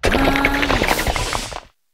varoom_ambient.ogg